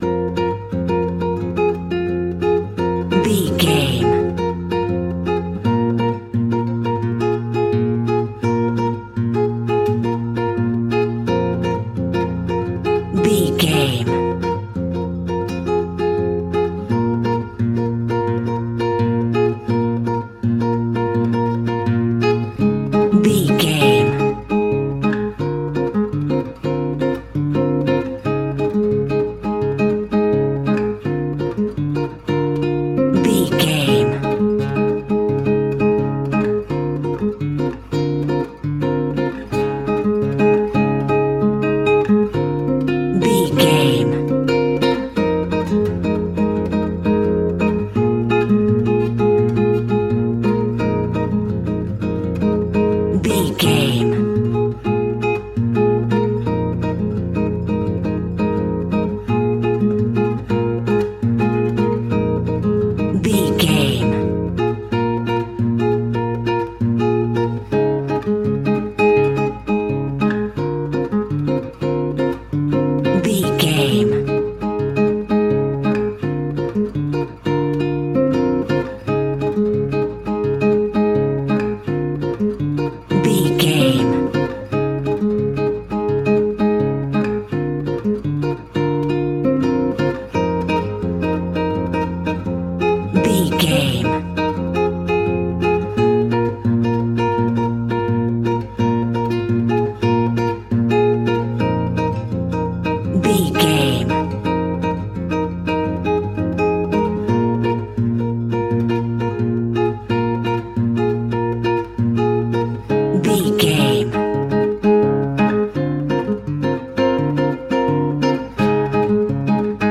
Aeolian/Minor
B♭
maracas
percussion spanish guitar